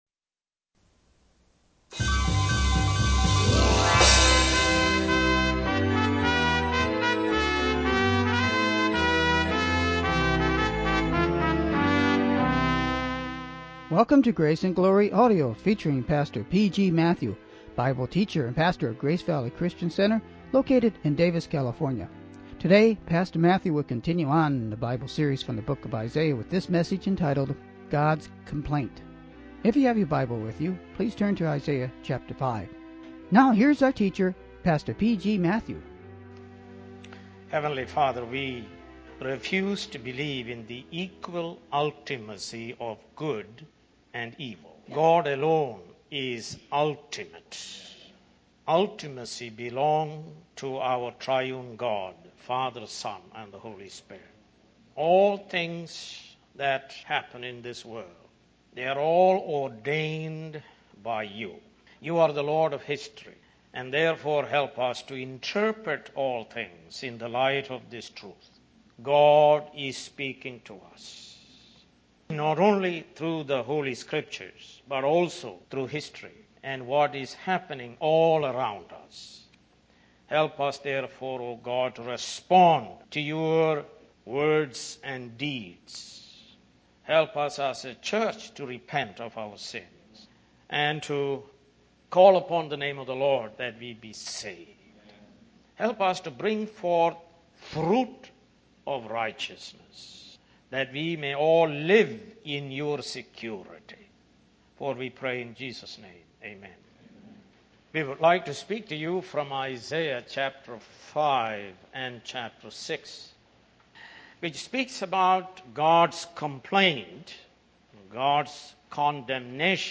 More Sermons From the book of Isaiah